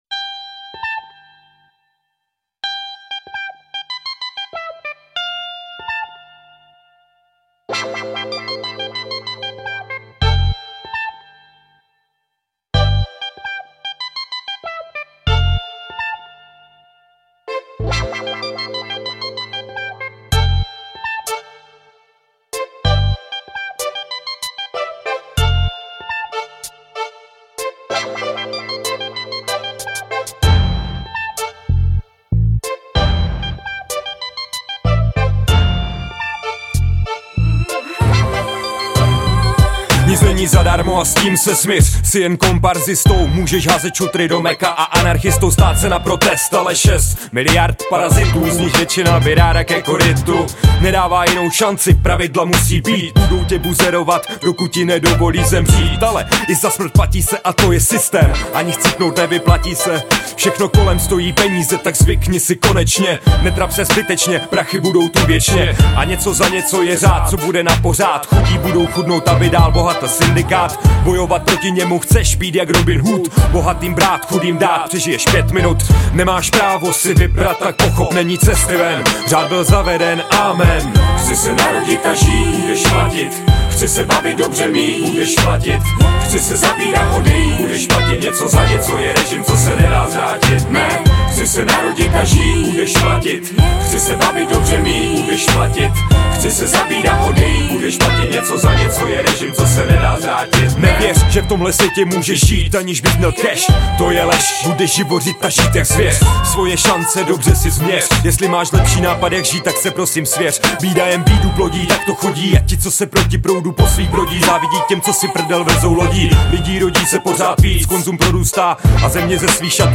14 Styl: Hip-Hop Rok